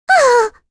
May-Vox_Damage_01.wav